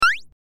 jump12.mp3